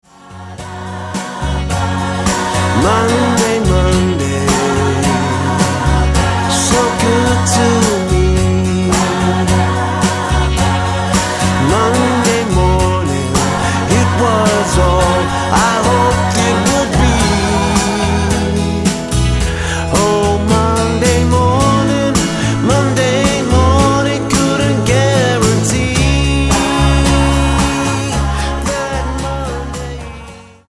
Tonart:F#-G# Multifile (kein Sofortdownload.
Die besten Playbacks Instrumentals und Karaoke Versionen .